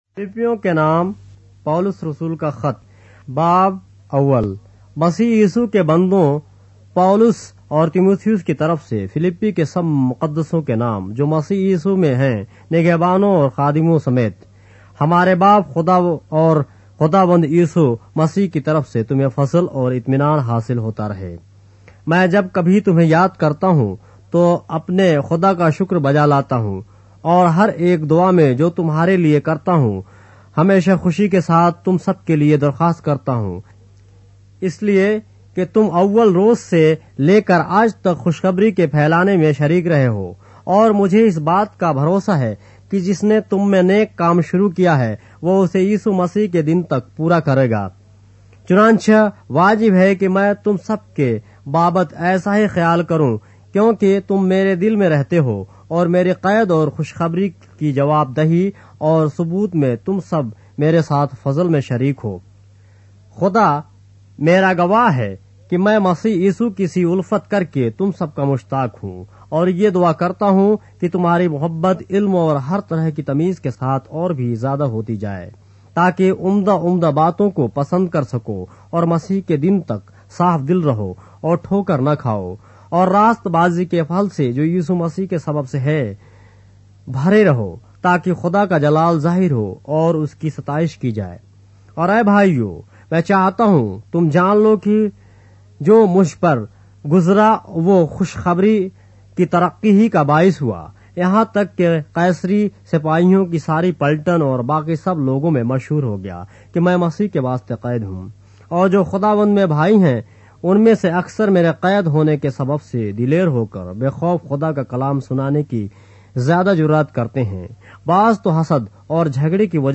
اردو بائبل کے باب - آڈیو روایت کے ساتھ - Philippians, chapter 1 of the Holy Bible in Urdu